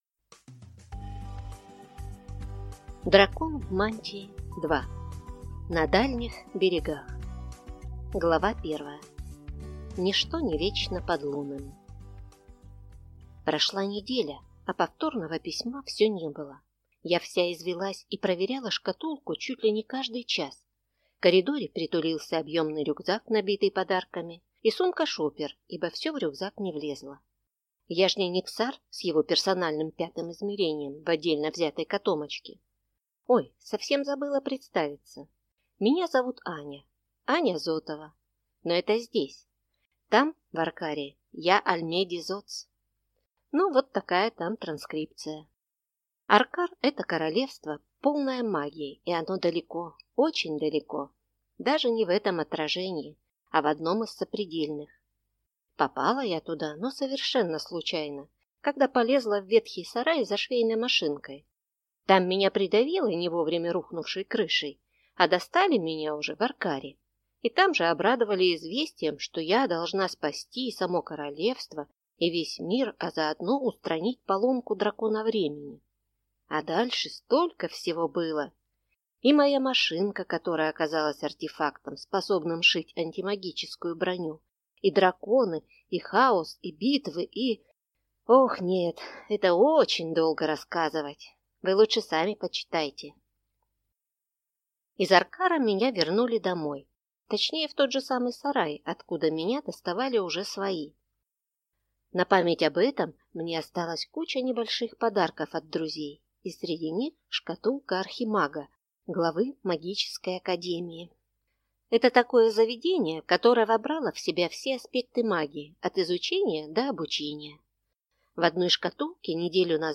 Аудиокнига Дракон в мантии – 2. На дальних берегах | Библиотека аудиокниг